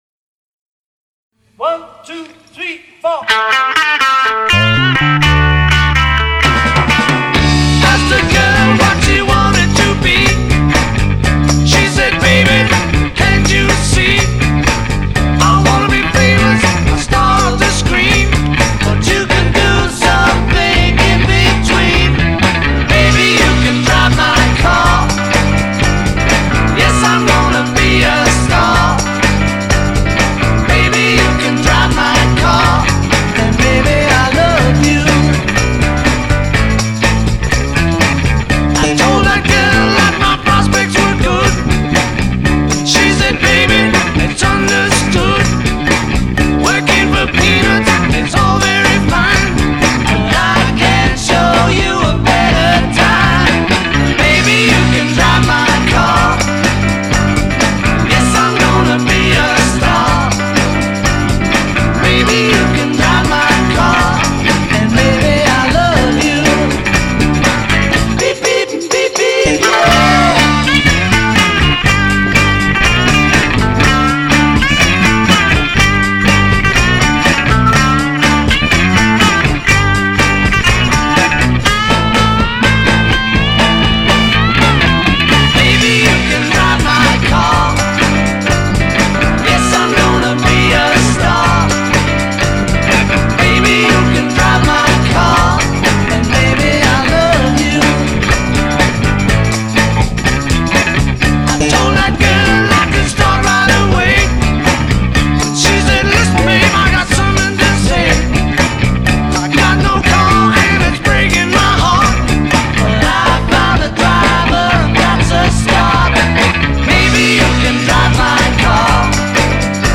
1- Yamaha BBNEII (activo 5 cuerdas)
2- Musicman Stingray (activo 5 cuerdas)
3- Ibanez GWB35 Gary Willis (activo fretless 5 cuerdas)
4- Fender Jazzbass (pasivo 4 cuerdas)
5- Hofner Beat Bass (pasivo 4 cuerdas)